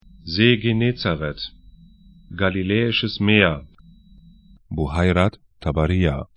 Galiläisches Meer   ze: ge'ne:-tsarɛt
gali'lɛ:ɪʃəs 'me:ɐ   Buhayrat Ţabarīyah bu'hairat taba'ri:ja ar See / lake 32°48'N, 35°38'E